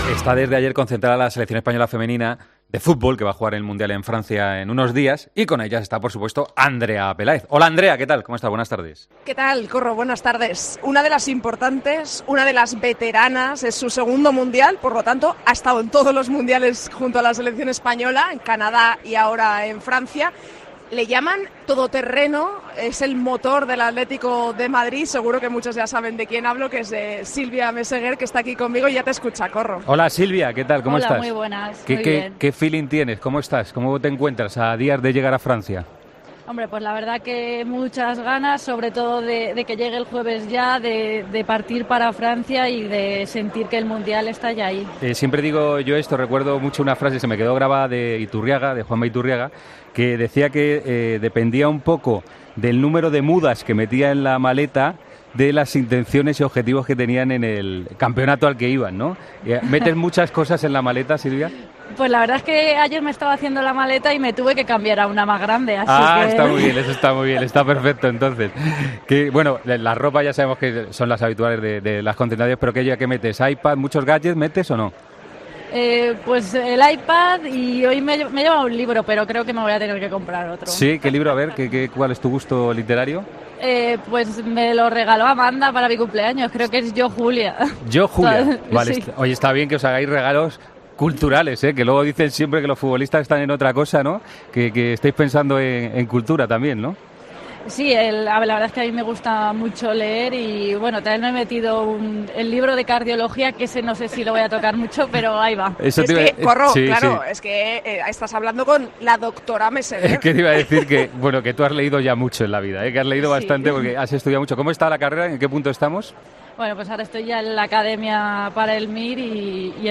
Hablamos con una de las jugadoras de la selección española de fútbol días antes del comienzo del Mundial.